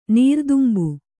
♪ nīrdumbu